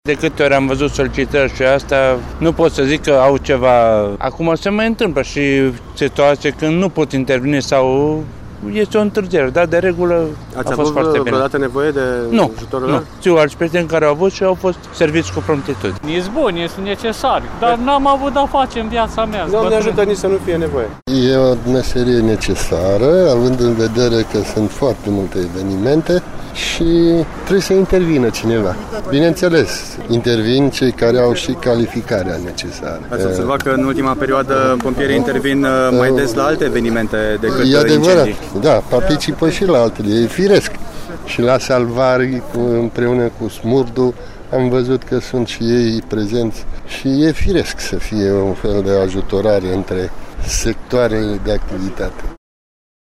vox-despre-ISU.mp3